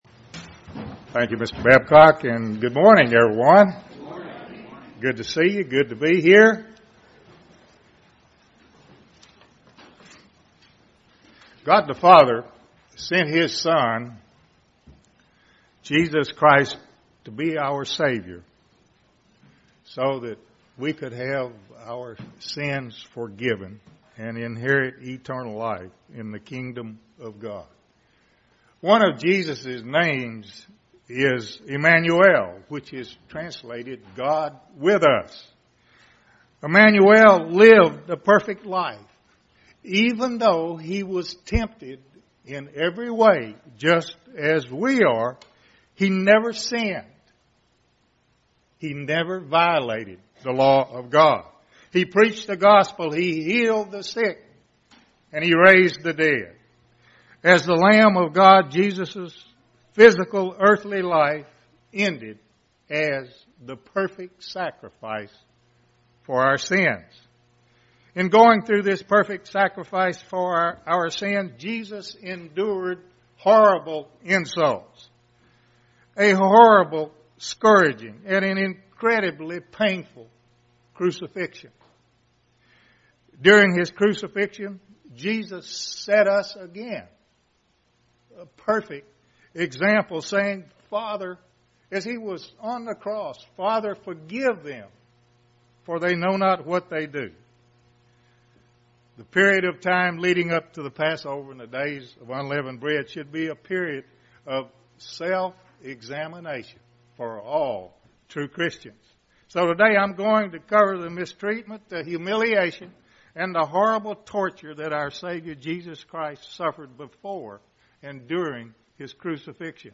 Given in Tulsa, OK
UCG Sermon Studying the bible?